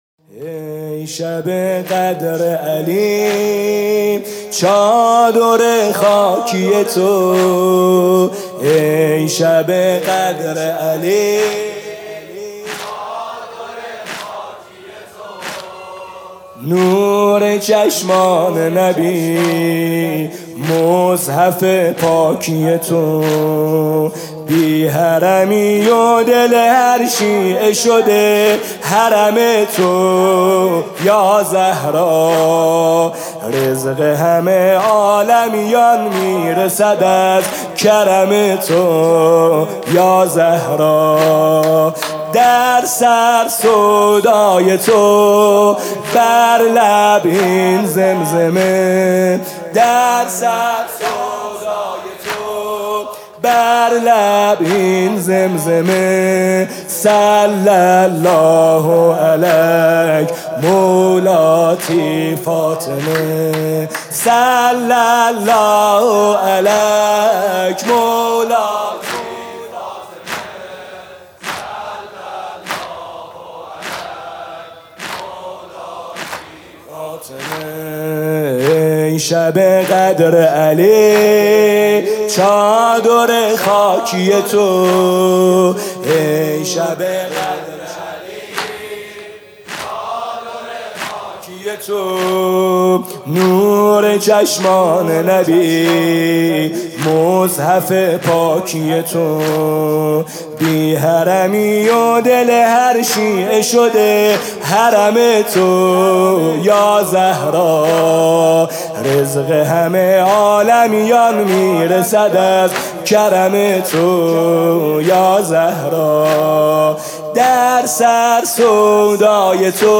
music-icon زمینه: نفس باد صبا از در و دیوار این خونه مشک فشا...